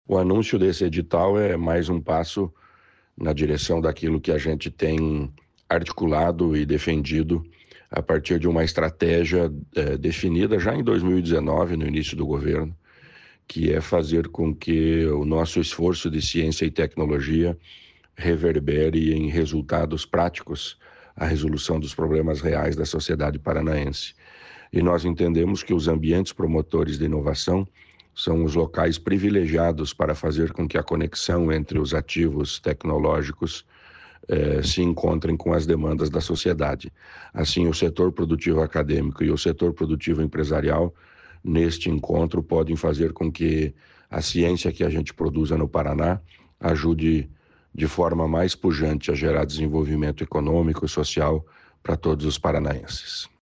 Sonora do secretário estadual da Ciência, Tecnologia e Ensino Superior, Aldo Nelson Bona, sobre novo edital para fortalecer o sistema estadual de inovação | Governo do Estado do Paraná